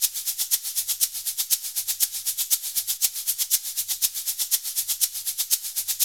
BRZ SHAKER1K.wav